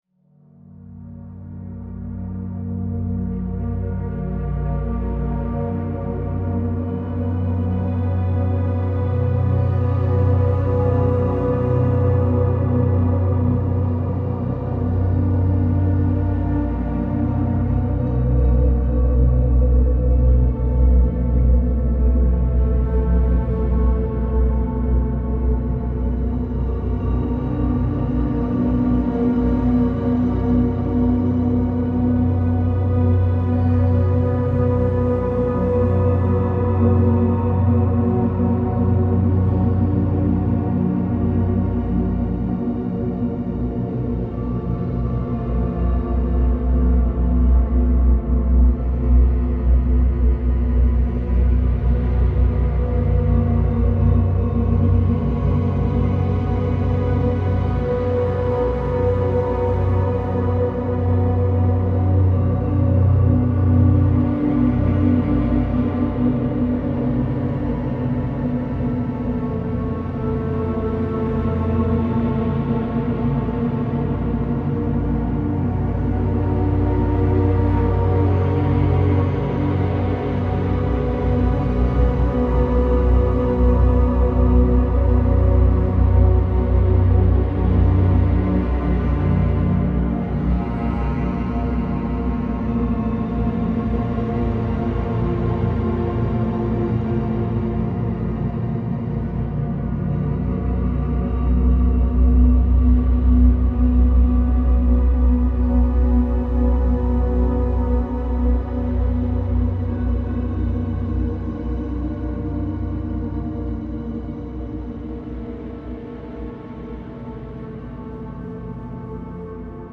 More Elden Ring inspired ambience